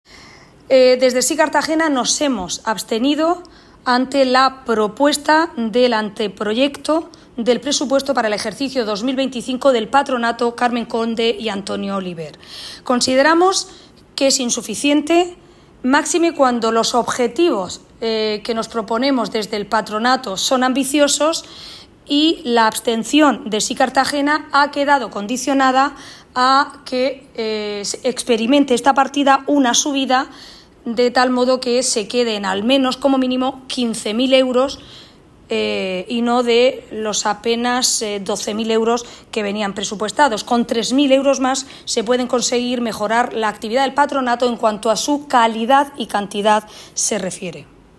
Audio: Declaraciones de Ana Bel�n Castej�n. (Corte 1) (MP3 - 1,12 MB)